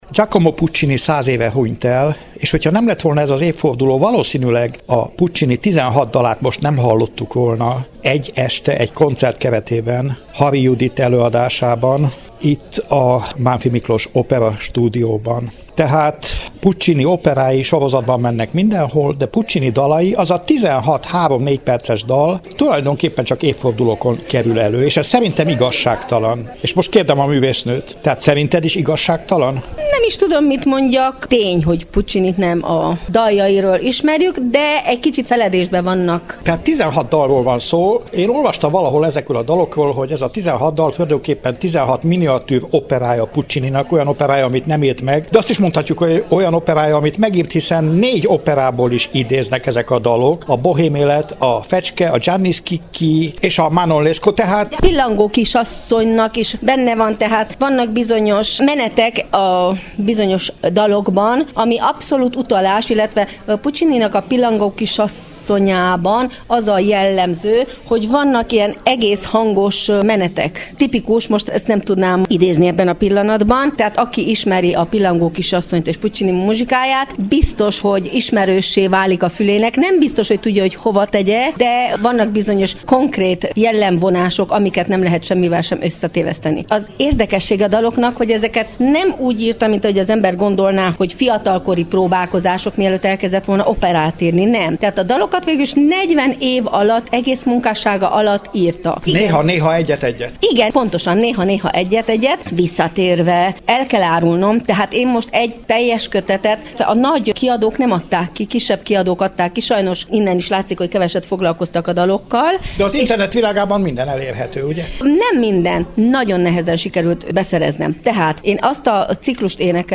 Ezt követően készült interjú